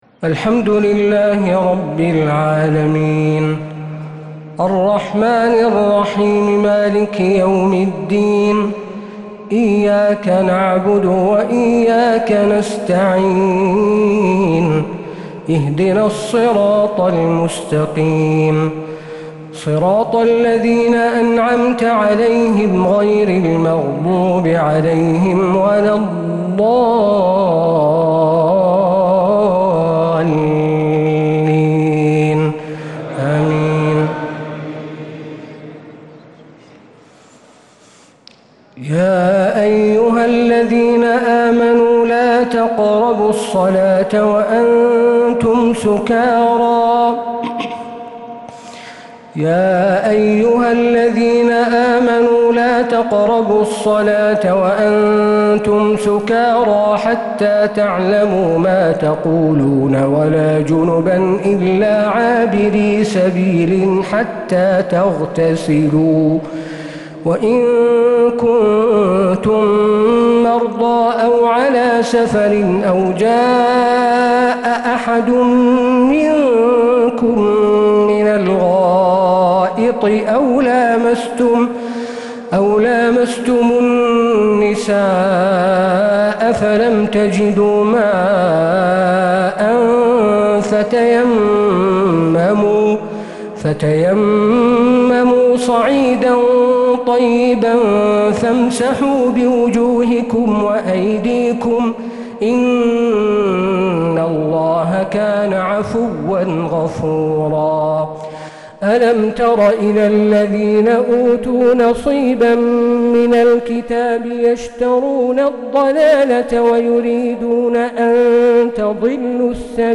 تراويح ليلة 6 رمضان 1446هـ من سورة النساء (43-87) | Taraweeh 6th night Ramadan 1446H Surah An-Nisaa > تراويح الحرم النبوي عام 1446 🕌 > التراويح - تلاوات الحرمين